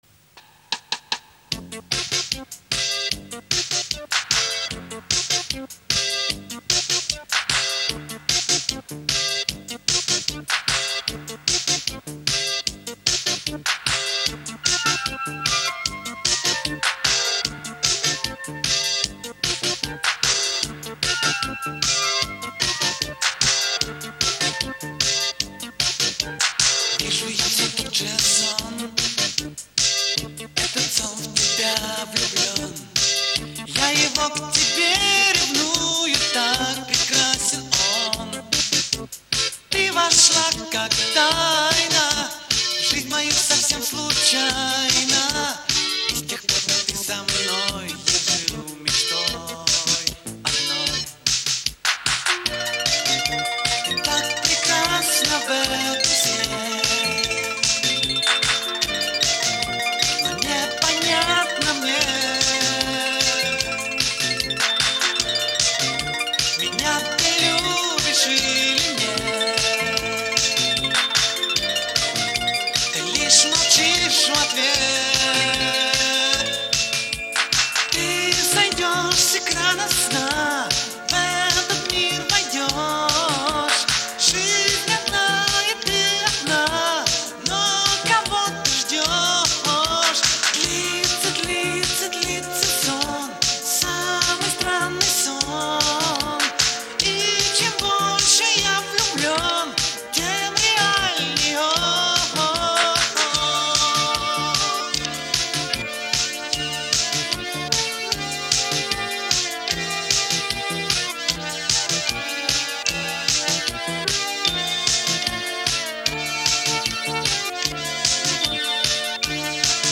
На касете звучит намного лучше, чем оцифровка.